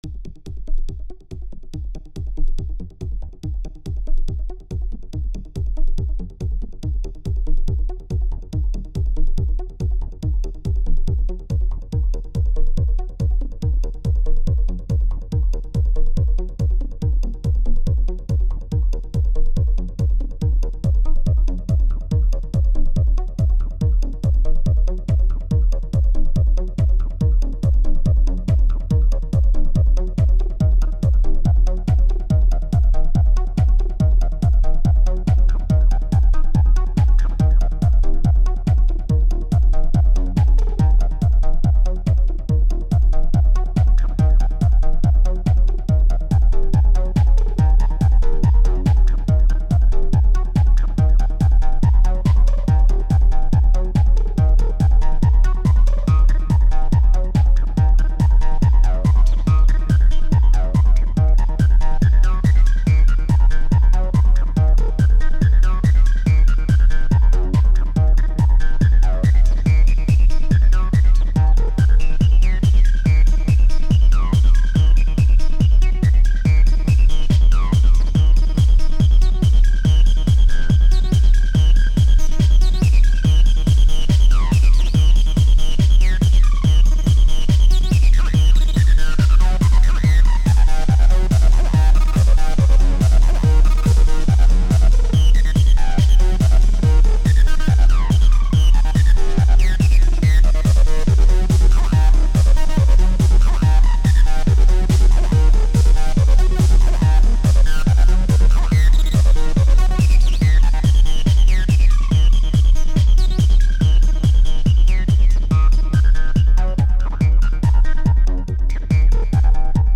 Made for OT but same principle : 16 plocked samples, 16 slices. Each sample is a loop with a different filter setting. One bar, morphing with crossfader, or Start of AR.